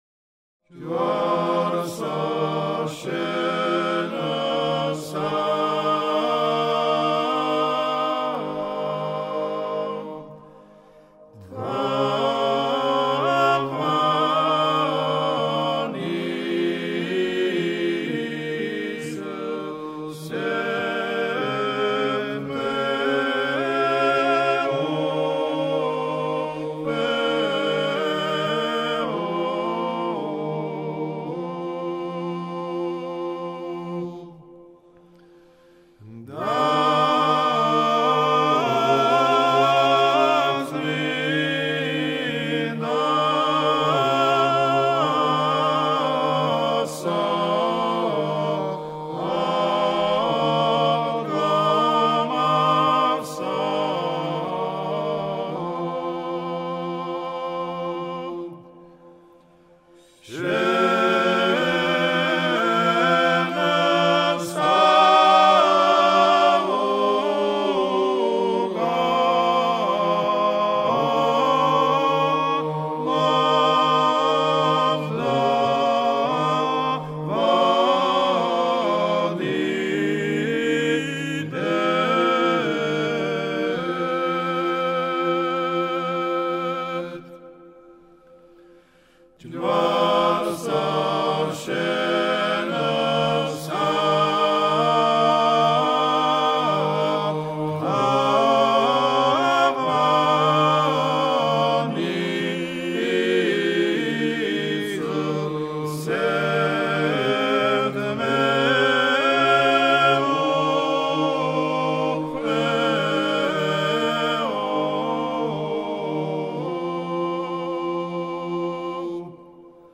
ქართული კანონიკური გალობა